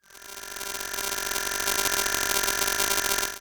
ihob/Assets/Extensions/RetroGamesSoundFX/Hum/Hum22.wav at master
Hum22.wav